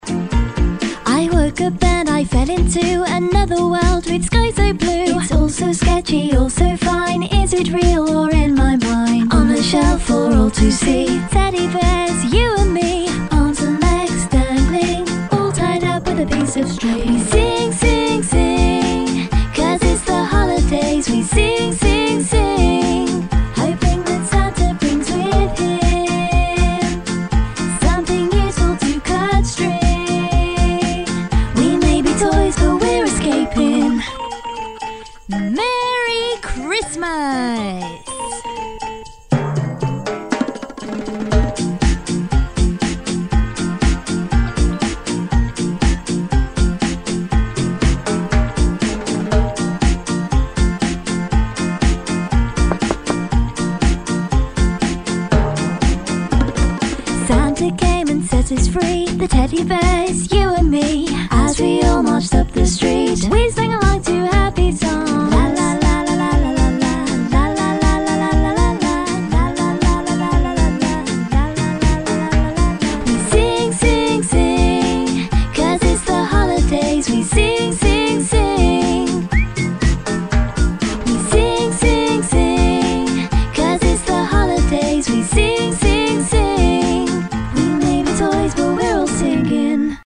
英式英语青年亲切甜美 、女课件PPT 、工程介绍 、绘本故事 、动漫动画游戏影视 、200元/百单词女英142 英式英语 女声 嫦娥后裔绘本故事 亲切甜美